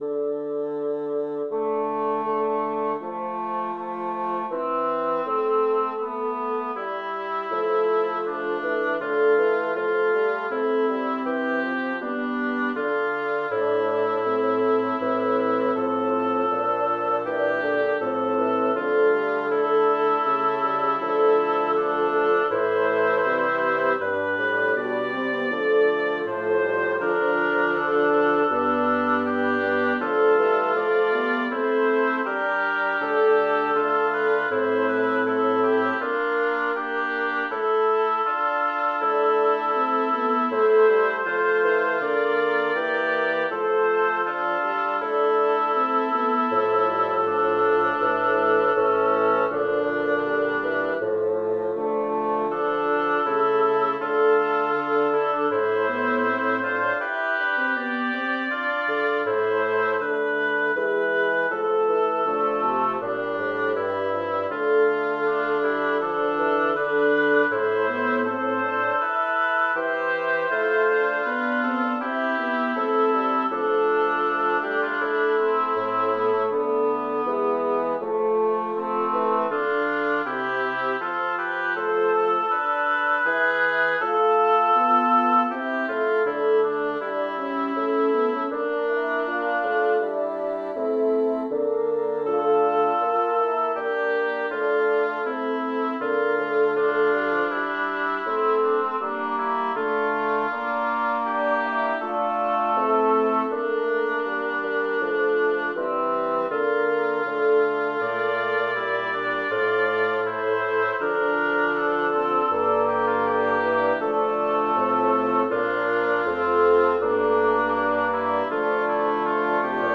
Title: Adiutor meus Composer: Francesco Stivori Lyricist: Number of voices: 5vv Voicing: SATTB Genre: Sacred, Motet
Language: Latin Instruments: A cappella